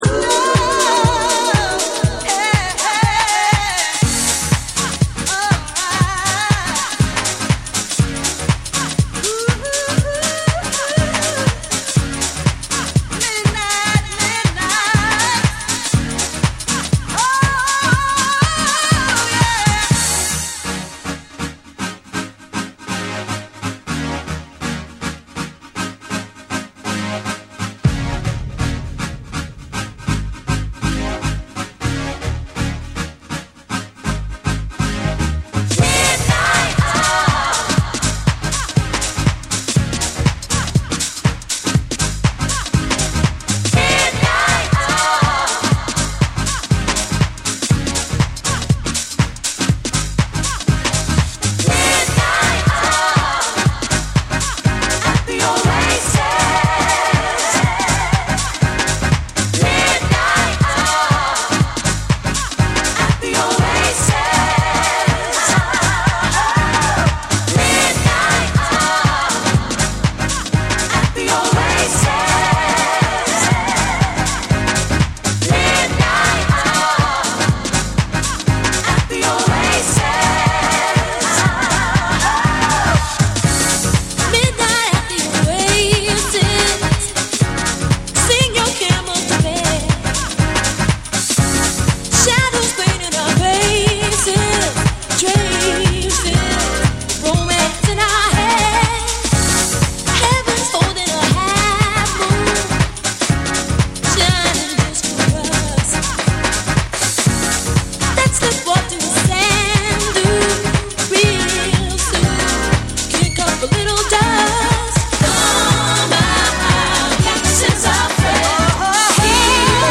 オリジナルのメロウな魅力を軸に、ハウス〜ブレイクビーツ感覚まで行き交う、フロア対応力の高い一枚。
TECHNO & HOUSE / SOUL & FUNK & JAZZ & etc